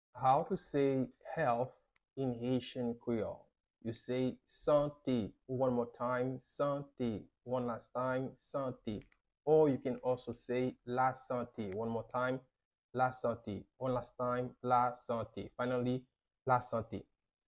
How to say "Health" in Haitian Creole - "Sante" pronunciation by a native Haitian teacher
“Sante” Pronunciation in Haitian Creole by a native Haitian can be heard in the audio here or in the video below:
How-to-say-Health-in-Haitian-Creole-Sante-pronunciation-by-a-native-Haitian-teacher.mp3